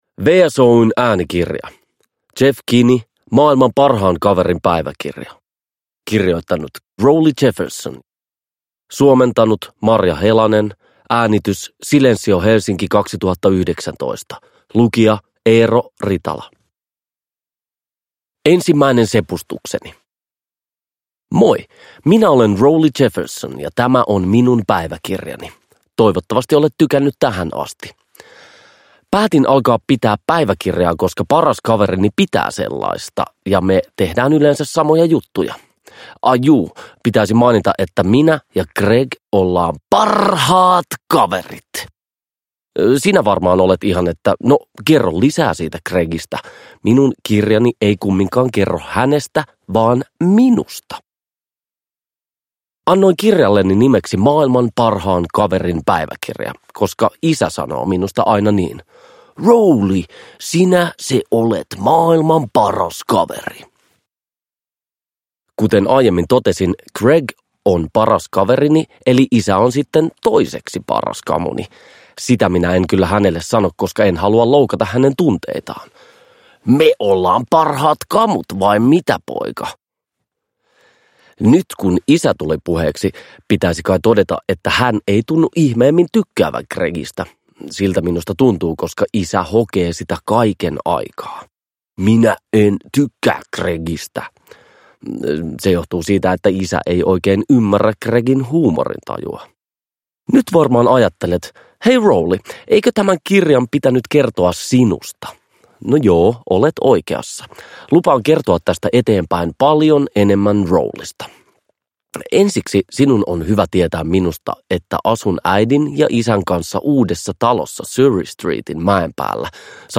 Maailman parhaan kaverin päiväkirja. Kirjoittanut Rowley Jefferson – Ljudbok – Laddas ner